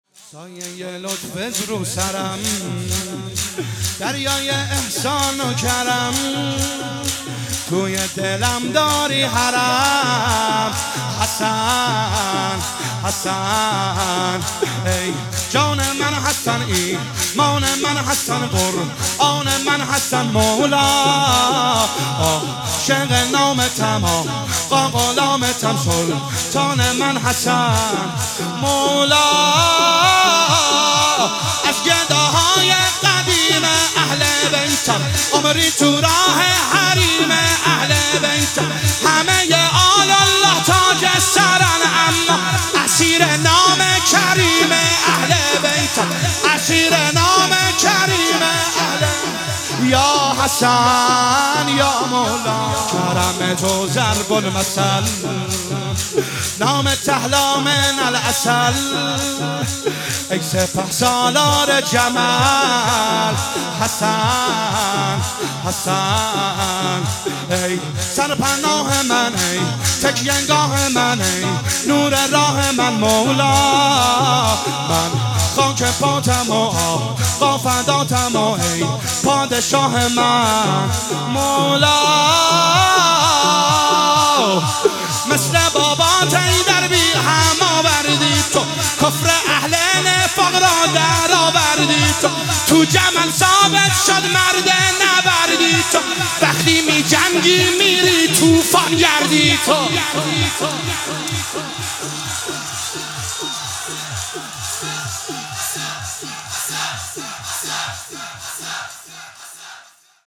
هیئت جنت العباس(ع) قم